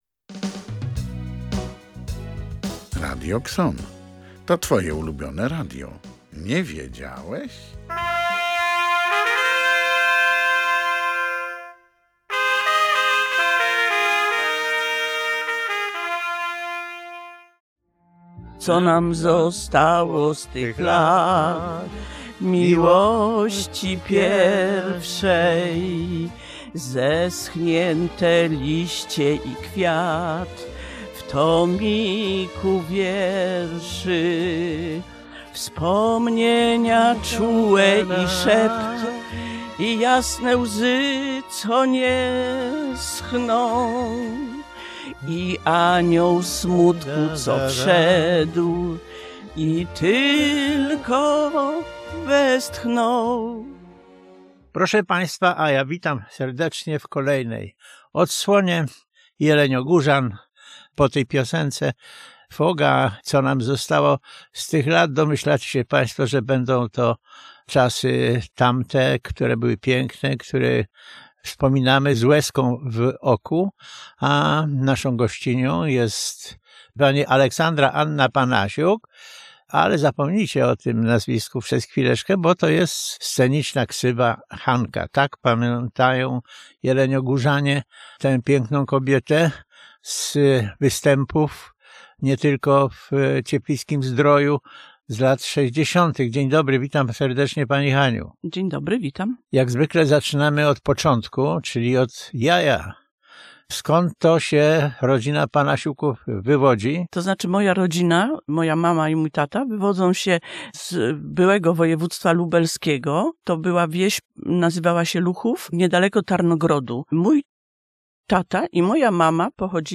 Dzisiejsza audycja to sentymentalna rozmowa